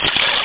FISHBITE.mp3